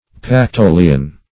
Search Result for " pactolian" : The Collaborative International Dictionary of English v.0.48: Pactolian \Pac*to"li*an\, a. Pertaining to the Pactolus, a river in ancient Lydia famous for its golden sands.